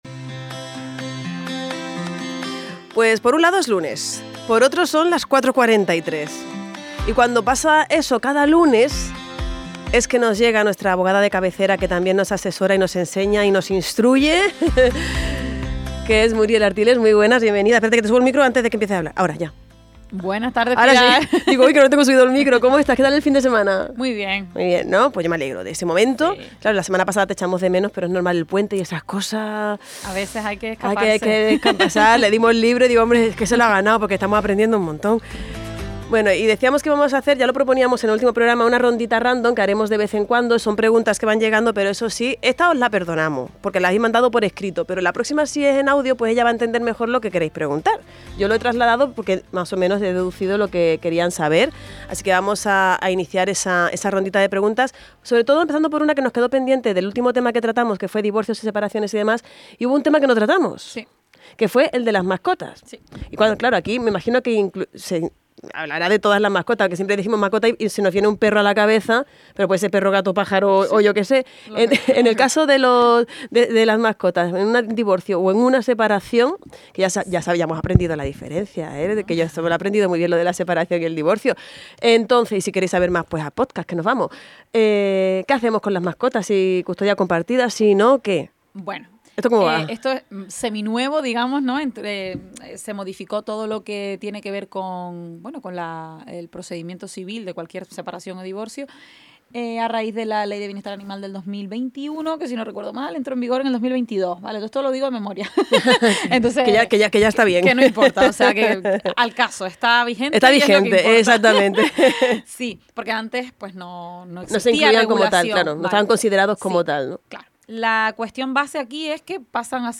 responde las preguntas de nuestros oyentes y aclara cuándo son necesarios o recomendados los servicios de un letrado.
Puedes realizar tus consultas cada lunes en la sección ‘Claro y Legal’ que se incluye en el programa EL TARDEO (16:00-19:00) de Radio Insular Fuerteventura.